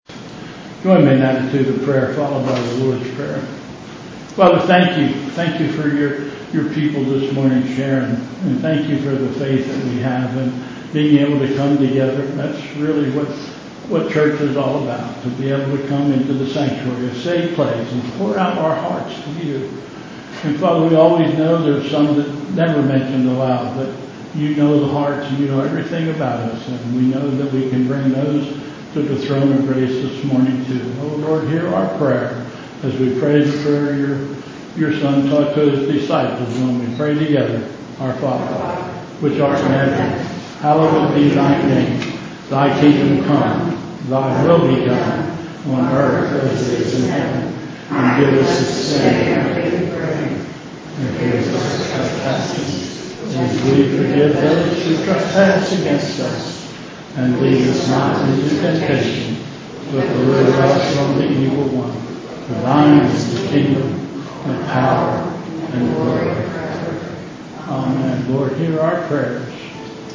Bethel Church Service
Pastoral Prayer...and the Lord's Prayer